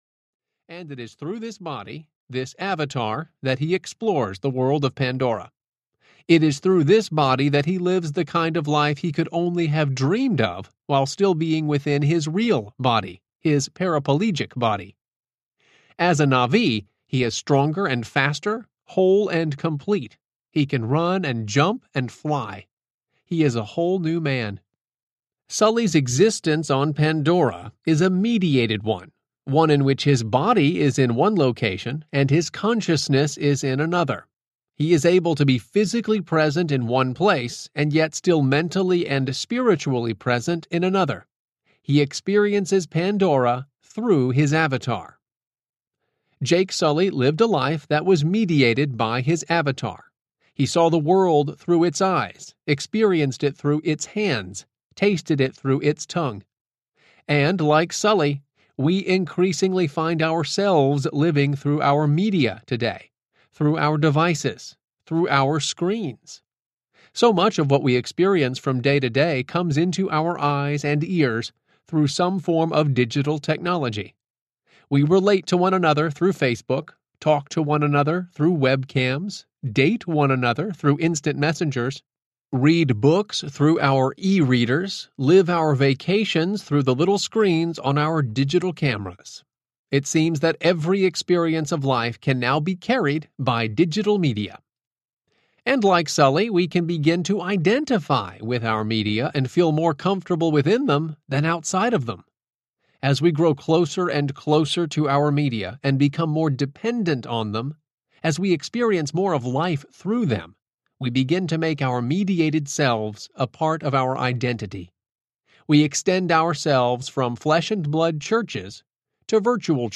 The Next Story Audiobook
Narrator
8.65 Hrs. – Unabridged